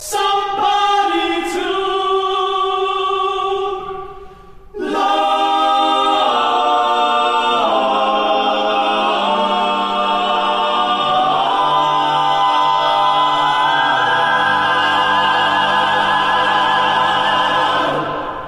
Key written in: A♭ Major
How many parts: 6
Type: Other male
All Parts mix: